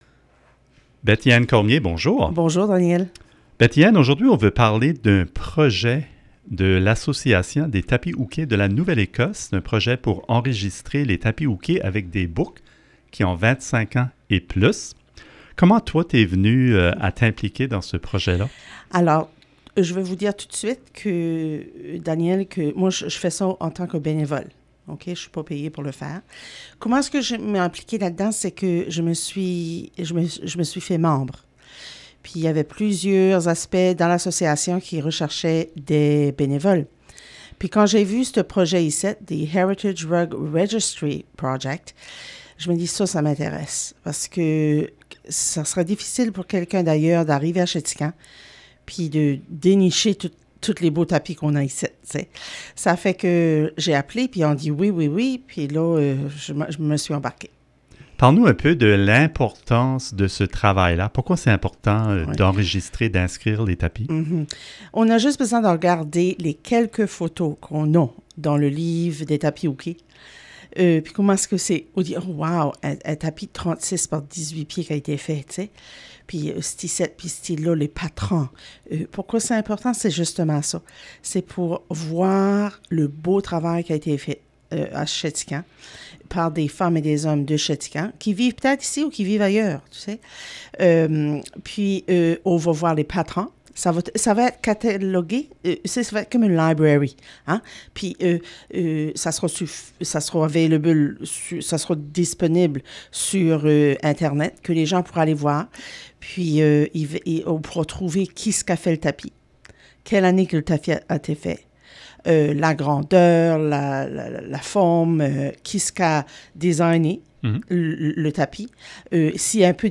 Lors d'un entretien sur les ondes de Radio CKJM